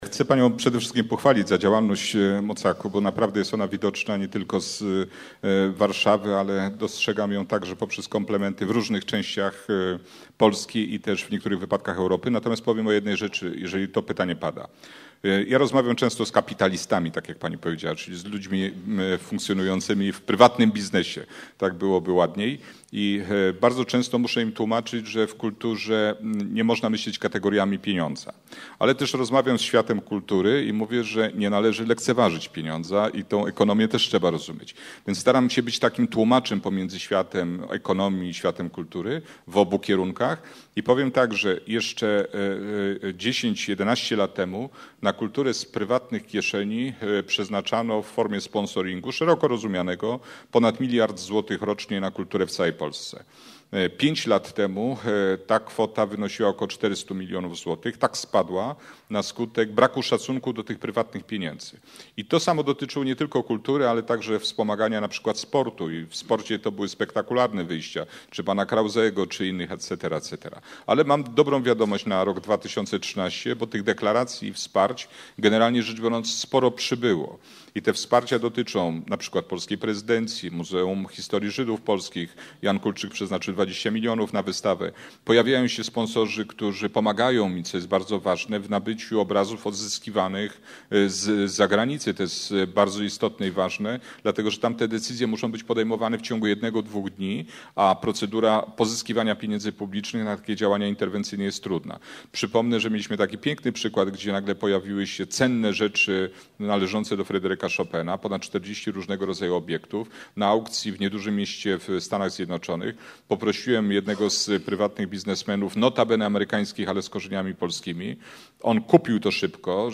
A Debate at Radio Krakow The Marriage of Culture and Business: Does It Work? - MOCAK